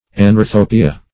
anorthopia \an`or*tho"pi*a\ ([a^]n`[o^]r*th[=o]"p[i^]*[.a]), n.